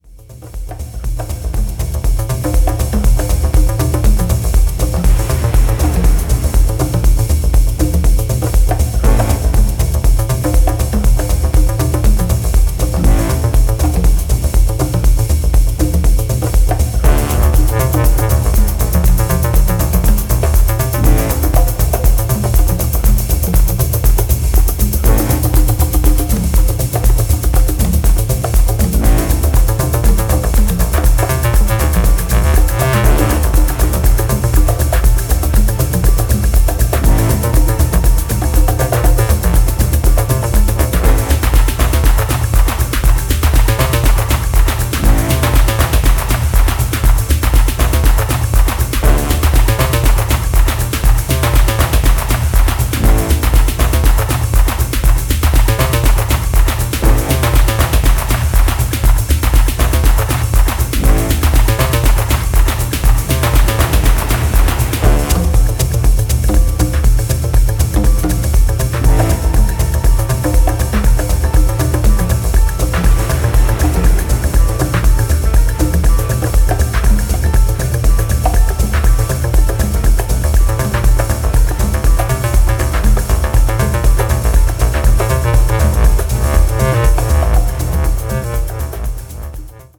Acid House , House